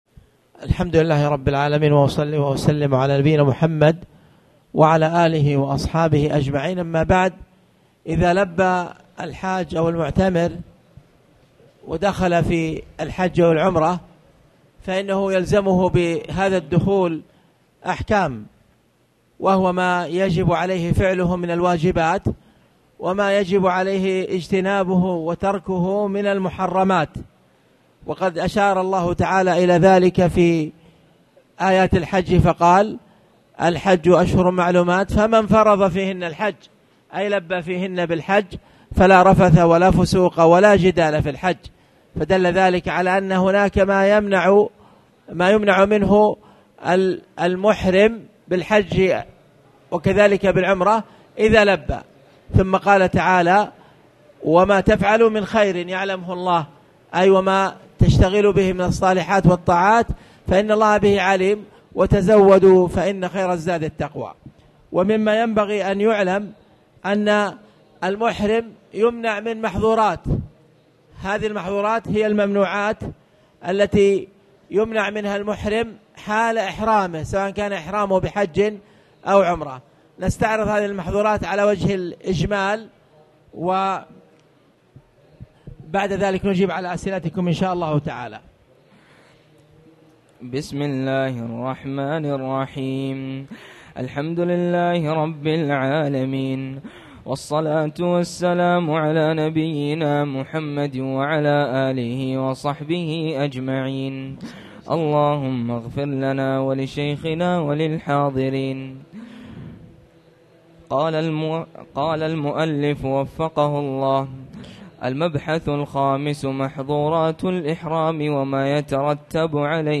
تاريخ النشر ٥ ذو الحجة ١٤٣٨ هـ المكان: المسجد الحرام الشيخ